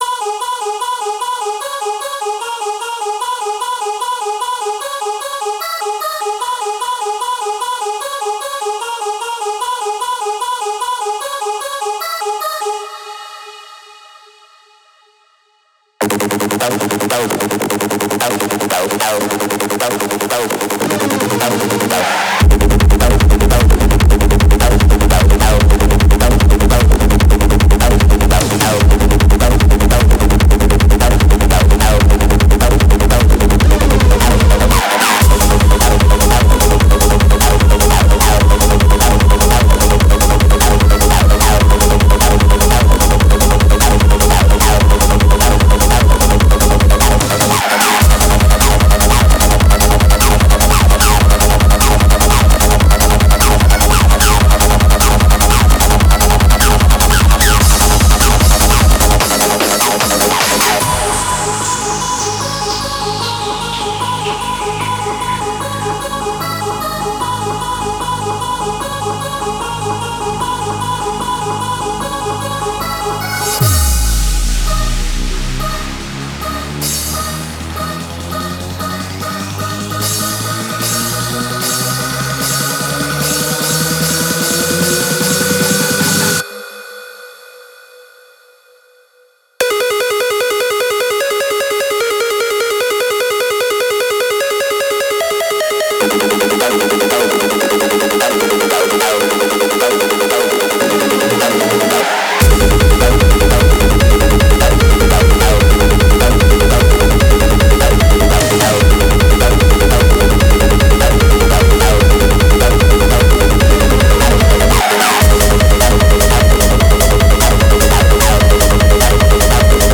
BPM150
Audio QualityPerfect (Low Quality)